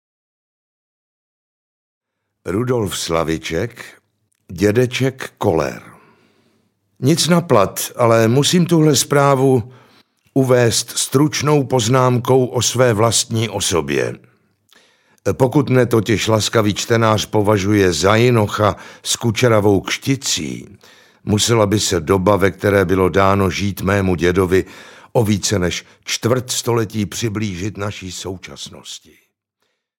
Dědeček Koller audiokniha
Ukázka z knihy
dedecek-koller-audiokniha